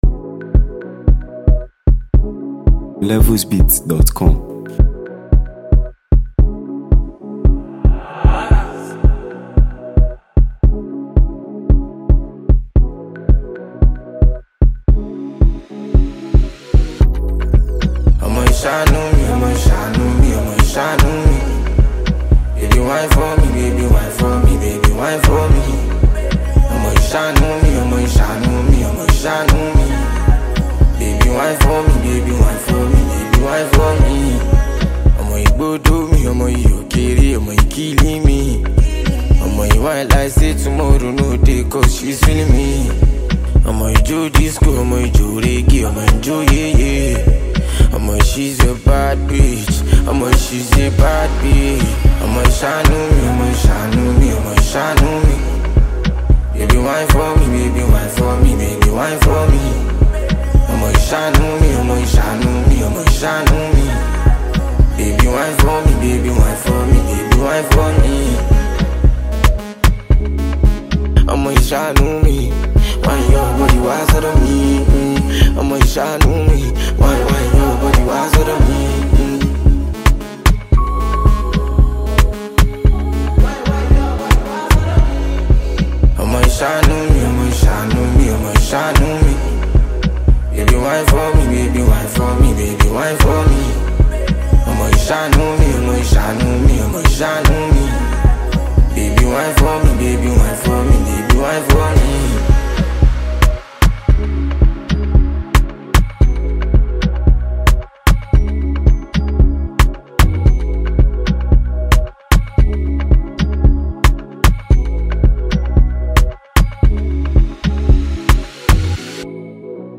Nigeria Music 2025 2:18